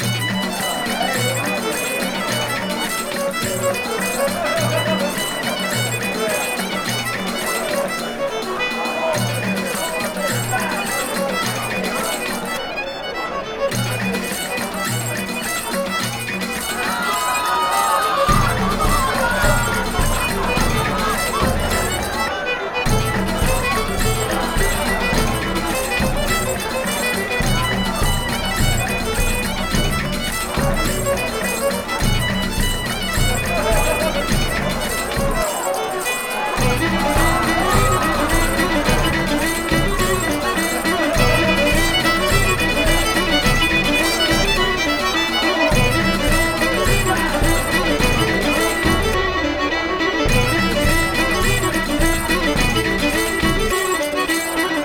tavern.ogg